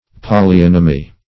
Meaning of polyonomy. polyonomy synonyms, pronunciation, spelling and more from Free Dictionary.
Search Result for " polyonomy" : The Collaborative International Dictionary of English v.0.48: Polyonomy \Pol`y*on"o*my\, n. [Cf. Gr.